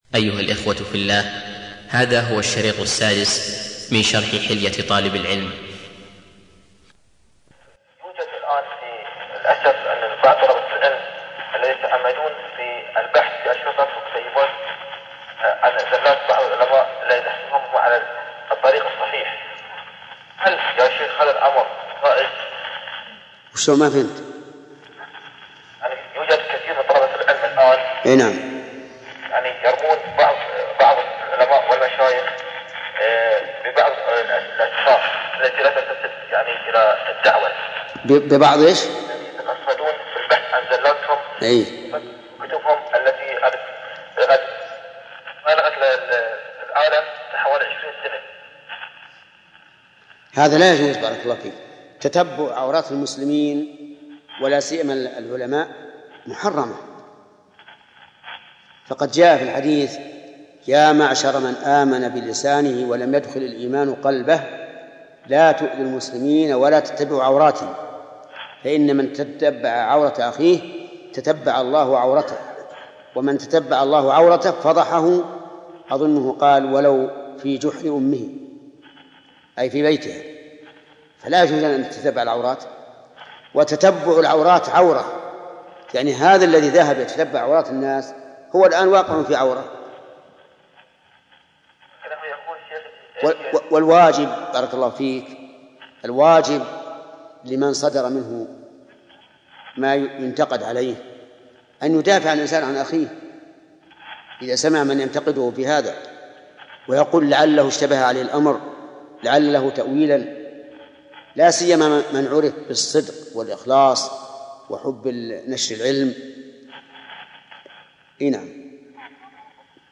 الدرس السادس - شرح كتاب حلية طالب العلم - فضيلة الشيخ محمد بن صالح العثيمين رحمه الله
عنوان المادة الدرس السادس - شرح كتاب حلية طالب العلم تاريخ التحميل الأثنين 21 اكتوبر 2013 مـ حجم المادة 41.96 ميجا بايت عدد الزيارات 870 زيارة عدد مرات الحفظ 400 مرة إستماع المادة حفظ المادة اضف تعليقك أرسل لصديق